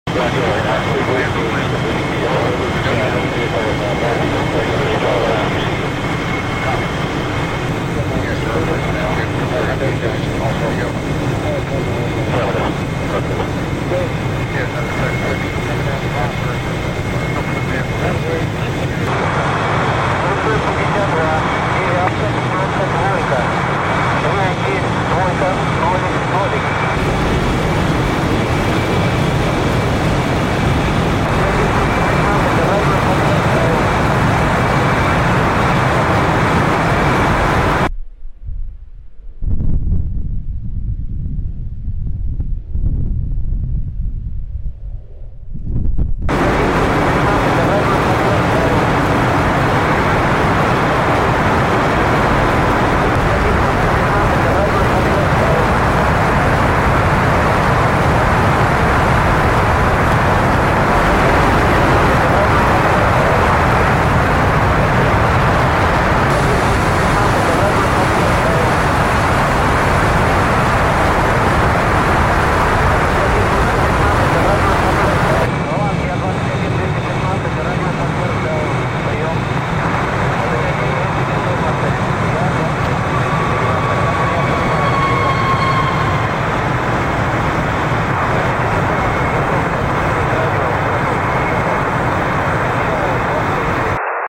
Russian SU 57 Felons are met sound effects free download
Russian SU-57 Felons are met by a pair of F-22 Raptors and a F-15E Strike Eagle over Syria
Created with DCS